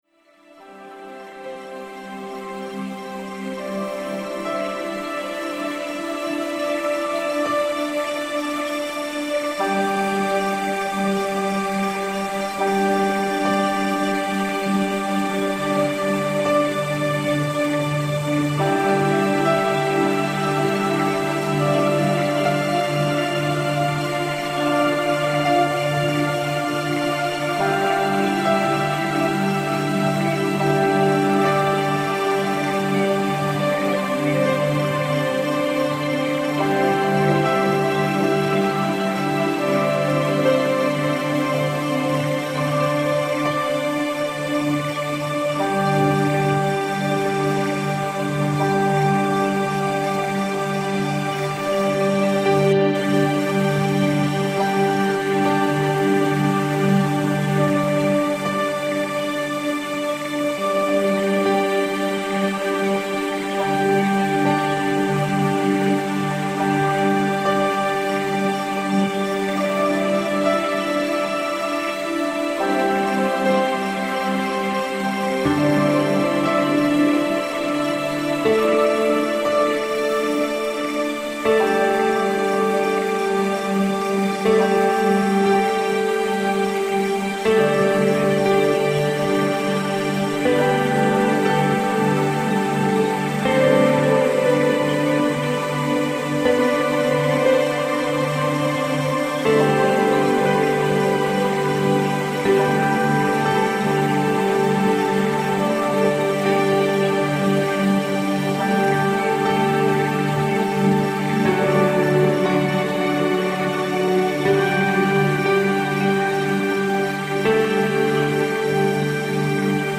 Медитация